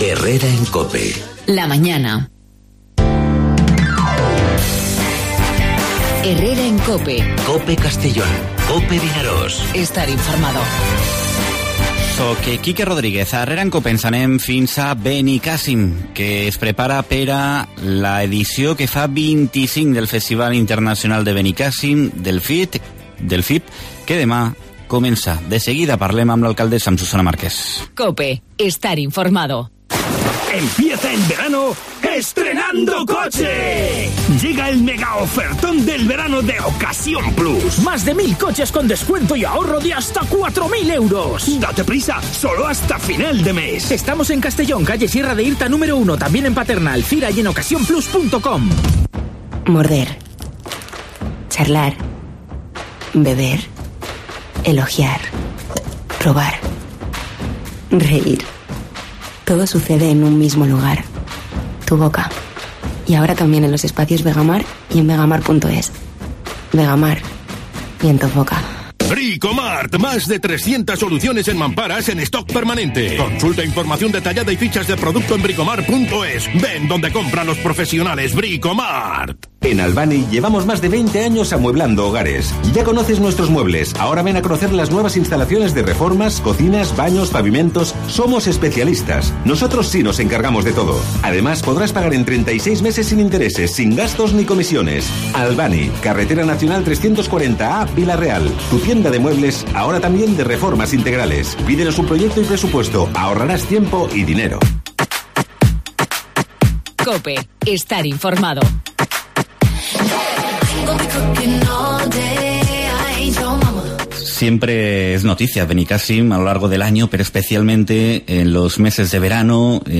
AUDIO: En 'Herrera en COPE' la alcaldesa de Benicàssim, Susana Marqués, habla de un FIB que se inicia mañana y que cumple su 25ª edición; y en...